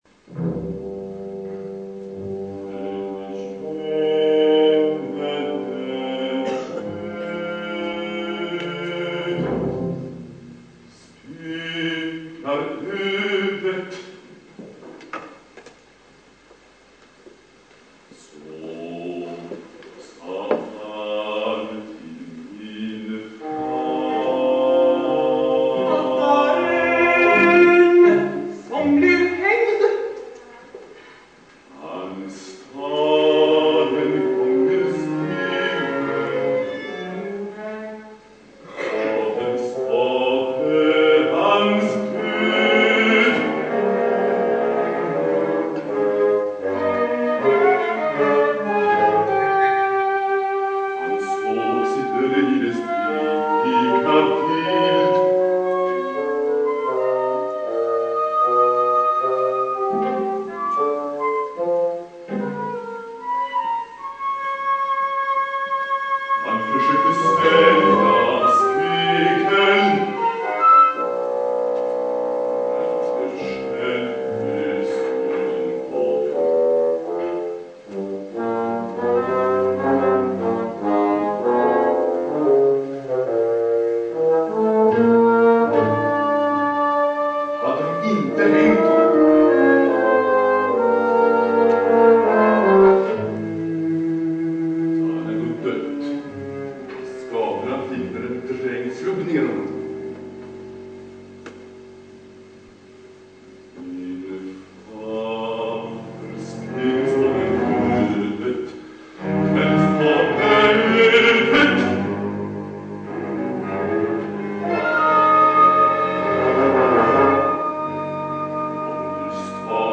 (två arior)
Kammaropera